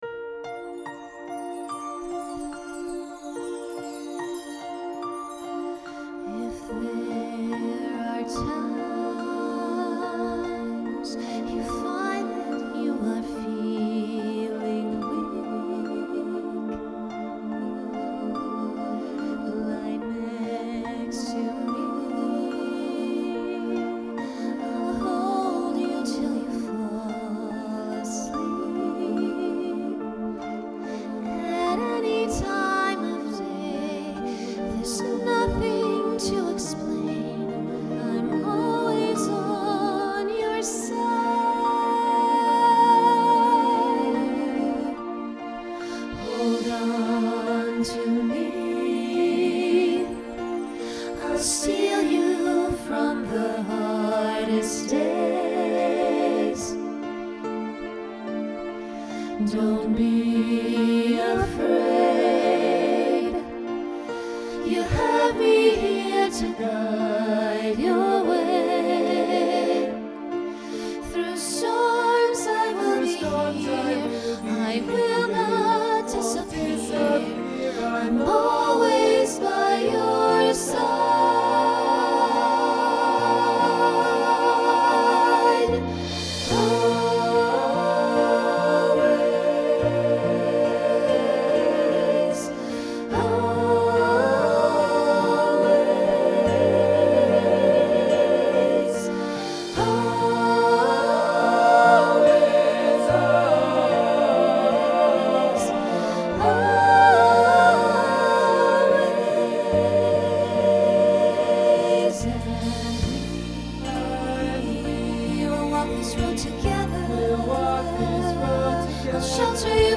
Voicing SATB Instrumental combo Genre Broadway/Film
Function Ballad